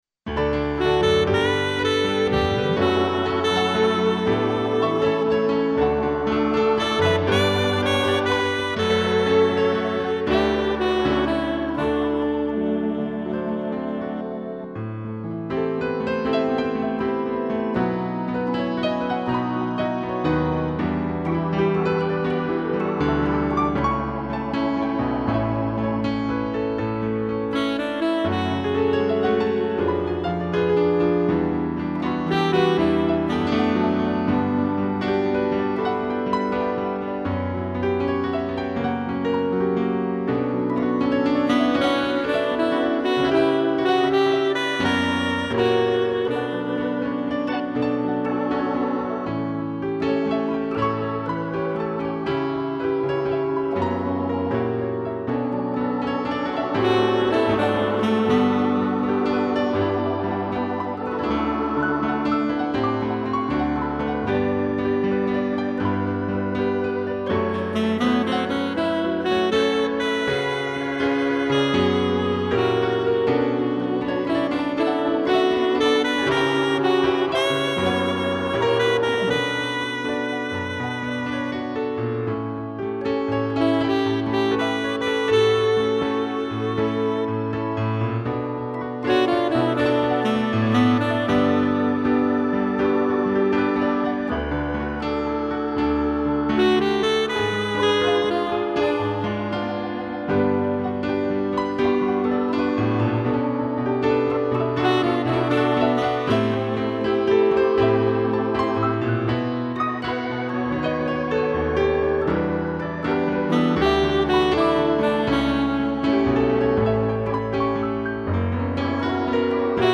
2 pianos, sax e órgão
(instrumental)